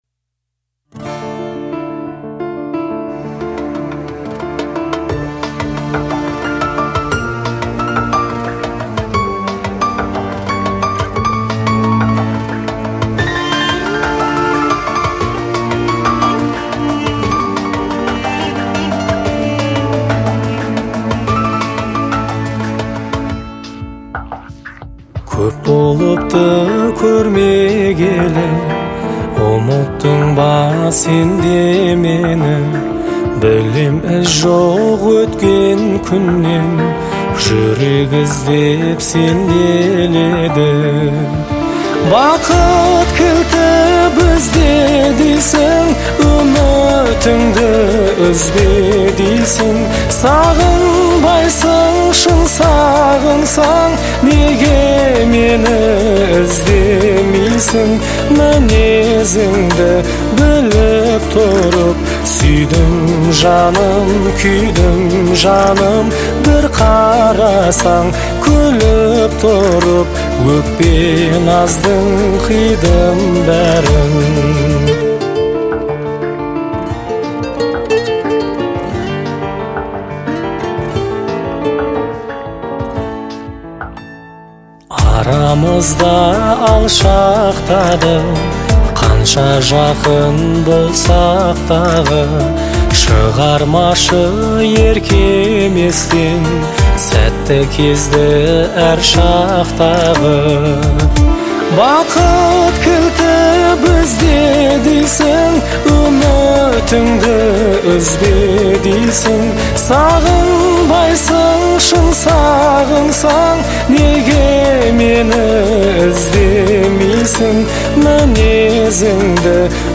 • Категория: Казахские песни